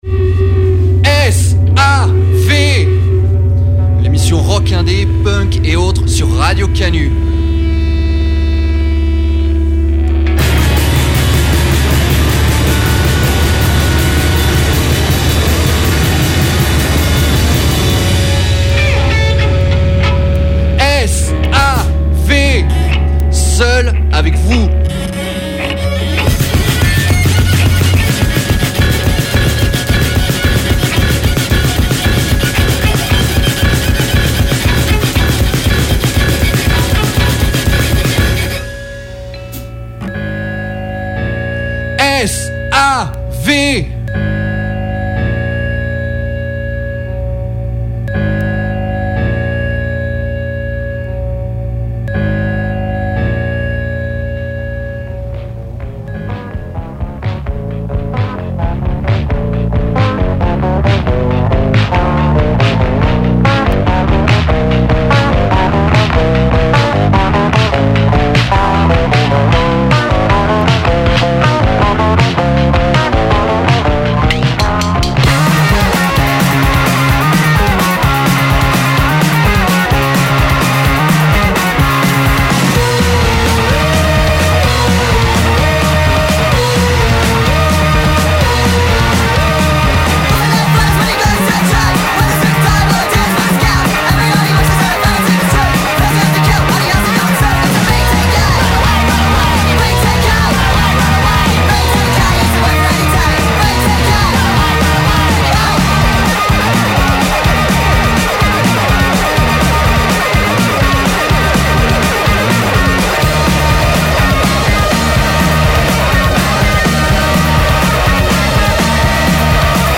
du punk japonais
la musique sautillante
des rééditions power pop
indiepop
soul
autres sorties indie, post-punk et autres